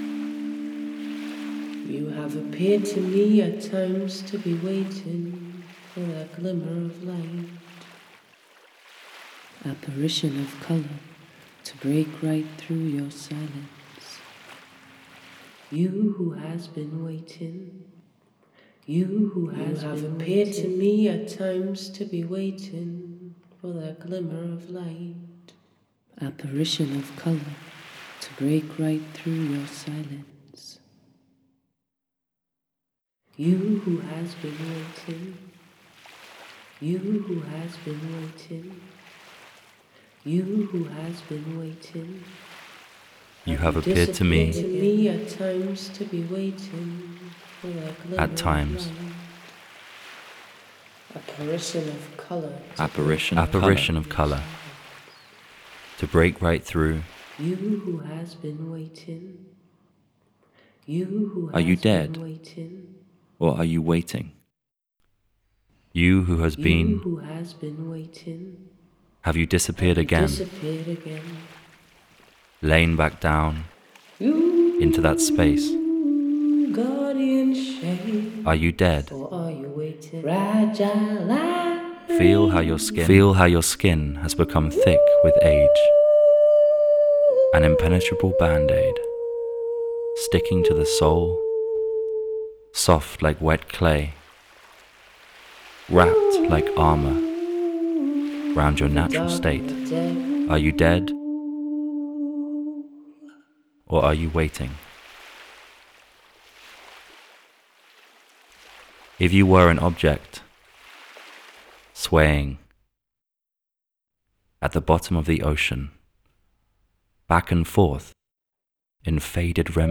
There are no silent objects, 2024, 4:42min Audio-Loop